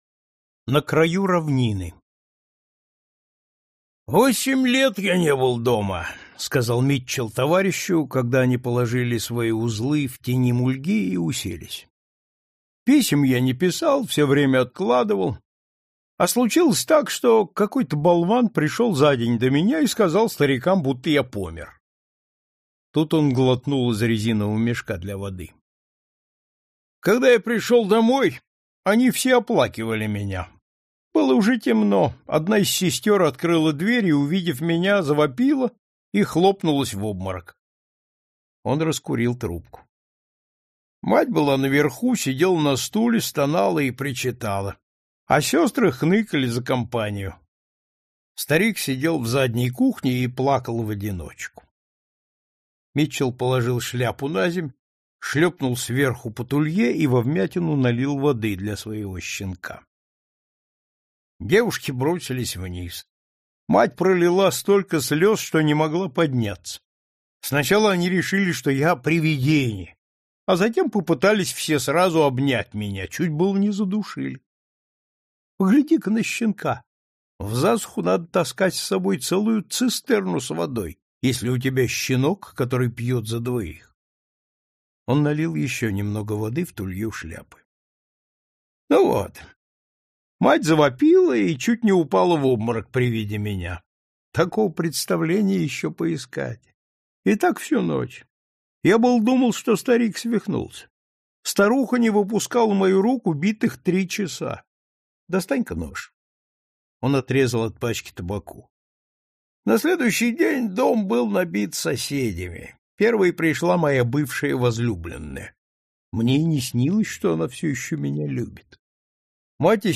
Аудиокнига Этот мой пес и другие рассказы | Библиотека аудиокниг